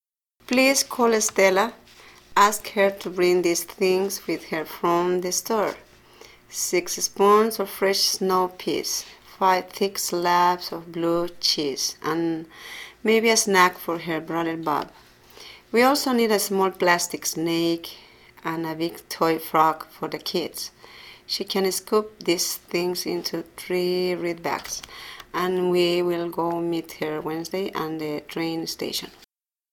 A Bolivian Accent
A native of Cochabamba
La oradora es nativa de Cochabamba, Bolivia, y se observa un fuerte acento en su pronunciación.